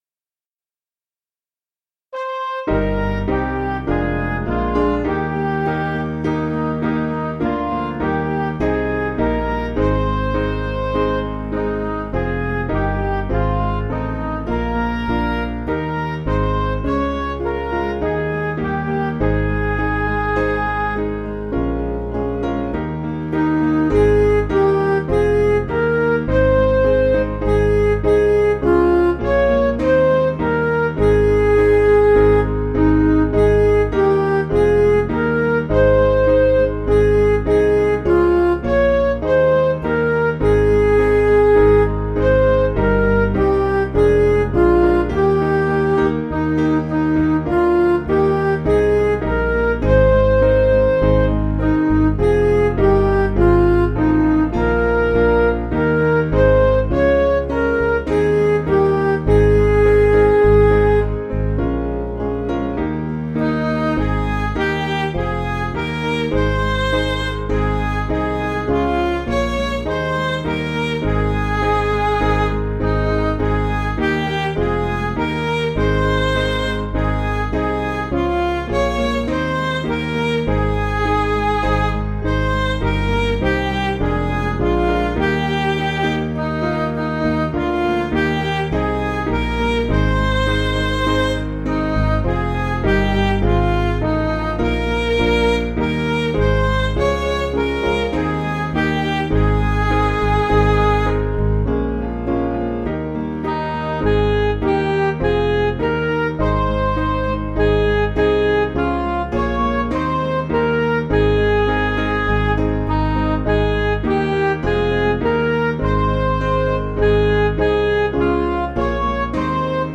Piano & Instrumental
(CM)   4/Ab
Midi